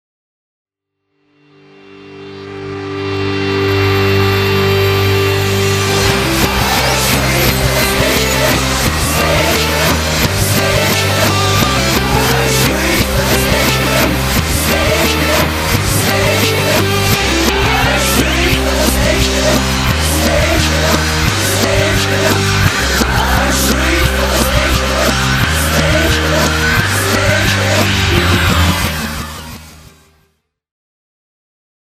pop rock
per cui si sente il pubblico in sottofondo.